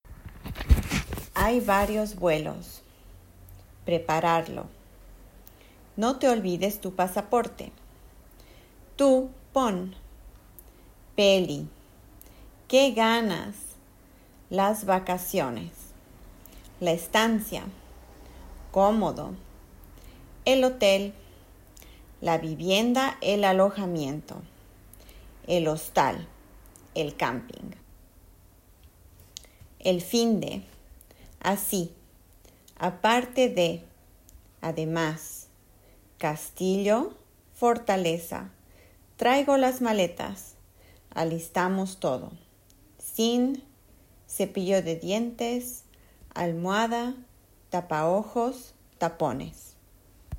La liste de vocabulaire